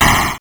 explosion_4.wav